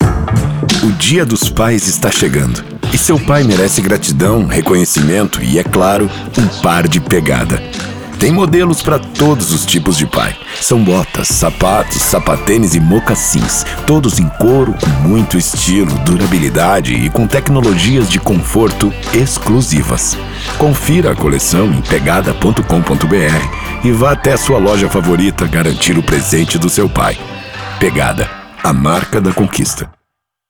Masculino
VAREJO HOMEM FORTE
Voz grave coloquial.